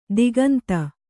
♪ diganta